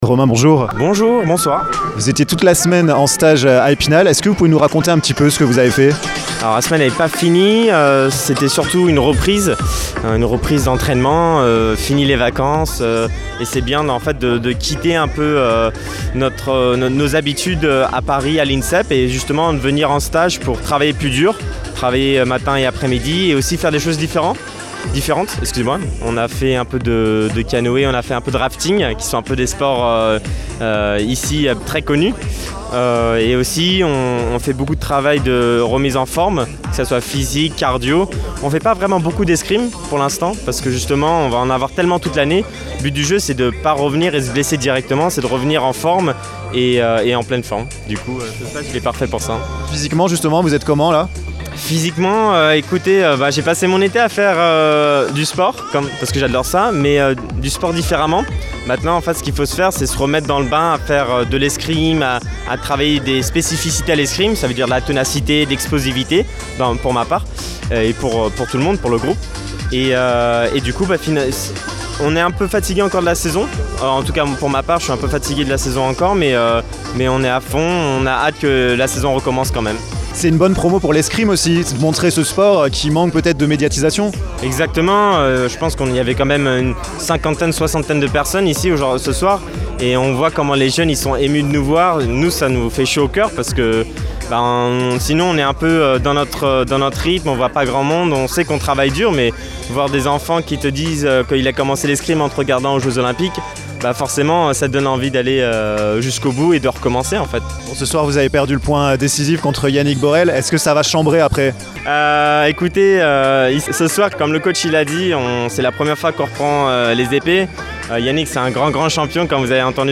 Une belle soirée d'escrime s'est déroulée jeudi 15 septembre grâce notamment à la société d'escrime spinalienne. Romain Cannone, le champion du monde et champion olympique, numéro 1 mondial, nous donne ses impressions au micro de Vosges FM!